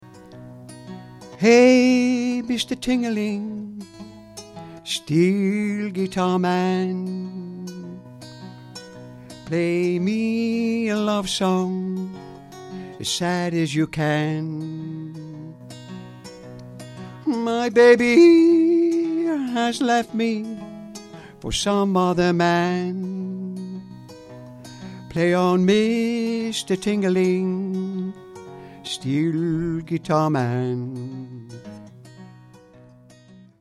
Ashington Folk Club - 01 February 2007
both whistled and yodelled this evening